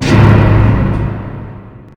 crash1.ogg